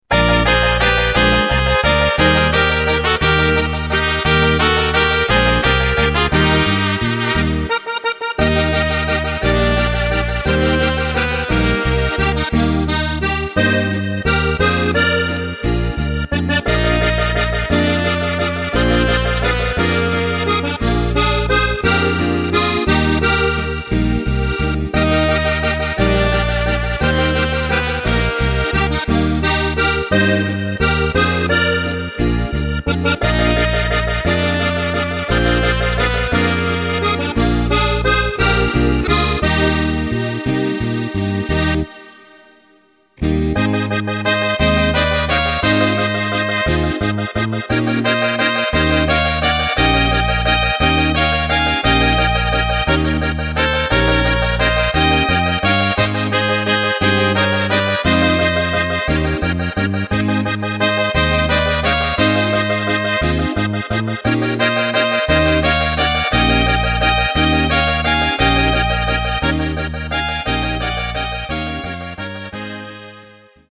Takt: 3/4 Tempo: 174.00 Tonart: Eb
Walzer Volksmusik!